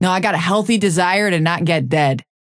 Vyper voice line - No, I got a healthy desire to not get dead.